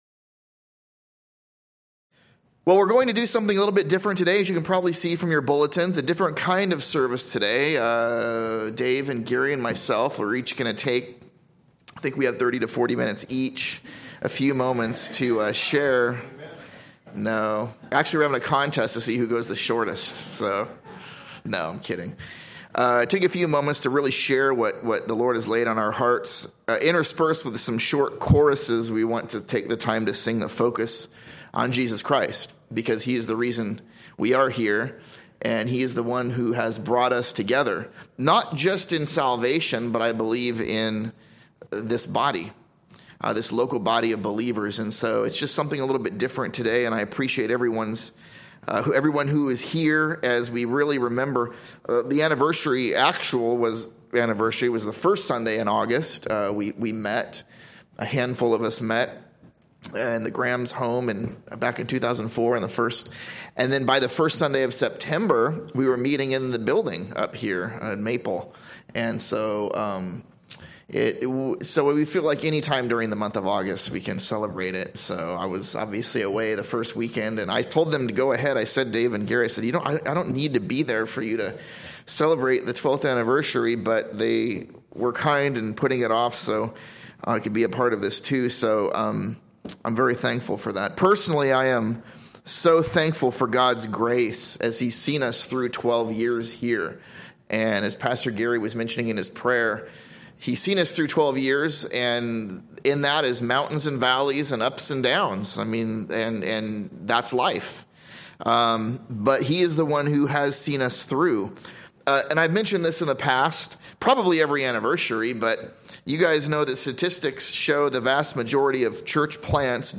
/ A Sunday School series through the book of Acts.